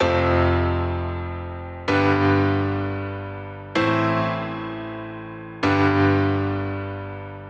Tag: 128 bpm Pop Loops Piano Loops 1.26 MB wav Key : F